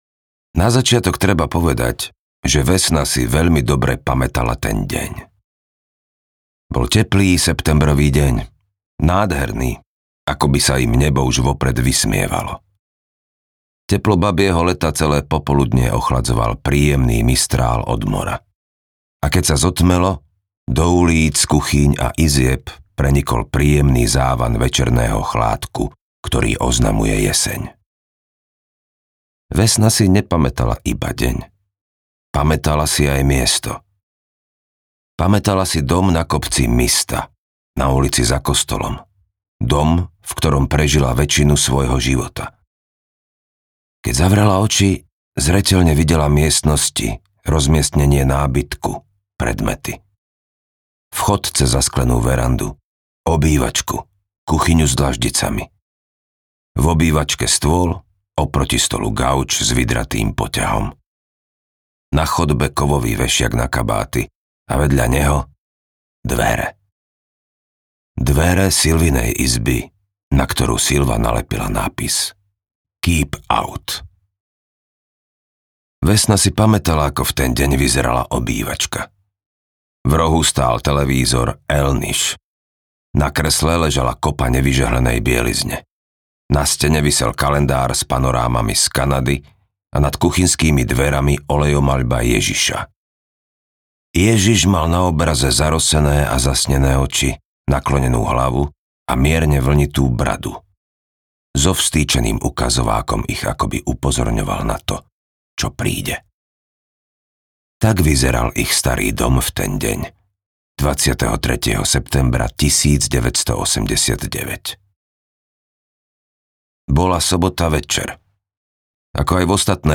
Krvavá voda audiokniha
Ukázka z knihy
krvava-voda-audiokniha